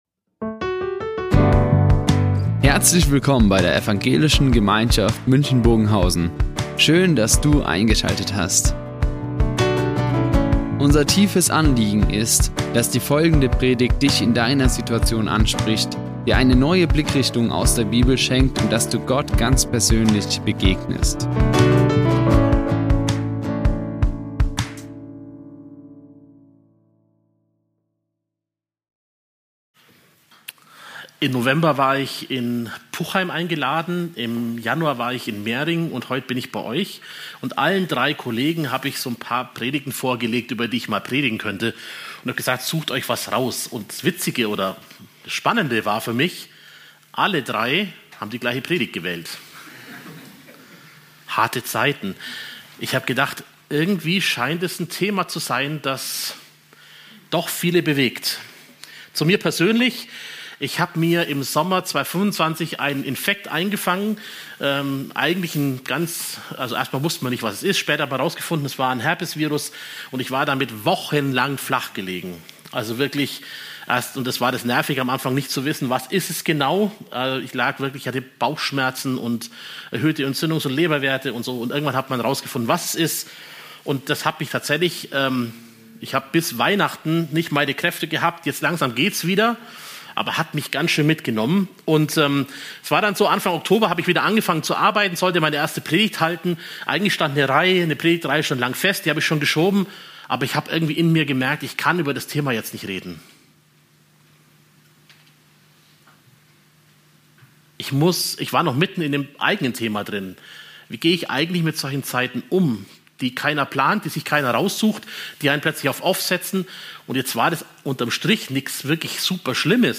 Harte Zeiten (unser Umgang mit Leid) | Predigt ~ Ev.
" Die Aufzeichnung erfolgte im Rahmen eines Livestreams.